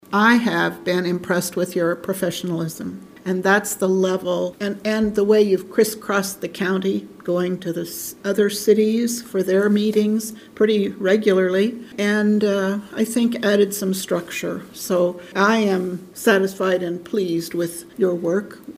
After three years and one month leading the Riley County Police Department, Riley County Police Department Director Dennis Butler sat in on his final Law Board meeting Tuesday afternoon at Manhattan City Hall.